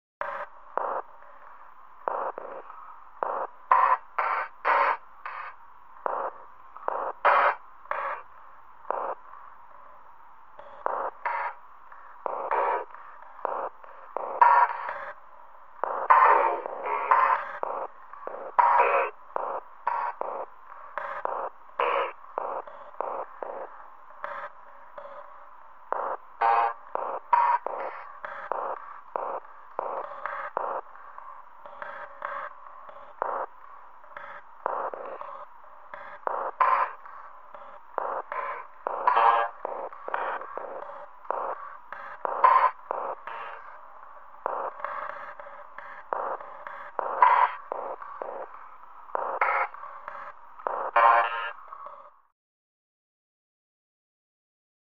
Ambience; Sci-fi Ambience --blips, Pings And Clicks.